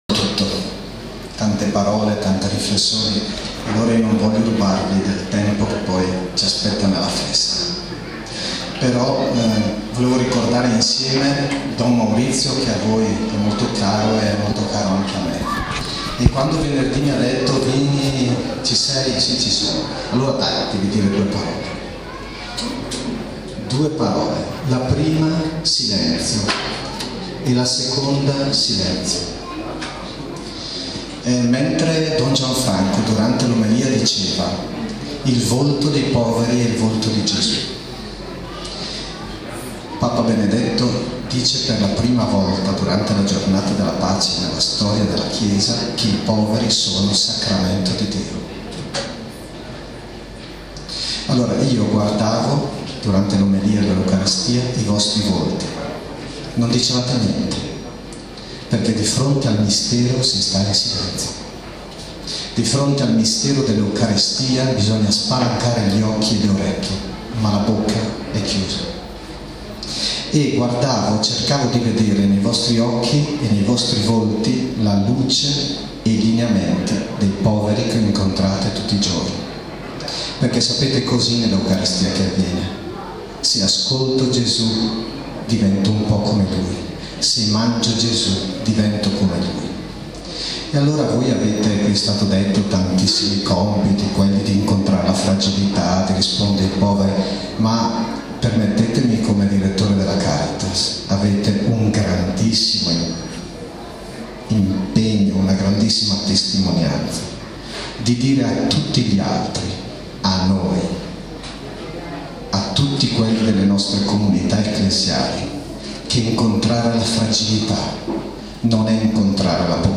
Inaugurazione dell'anno sociale 2009-2010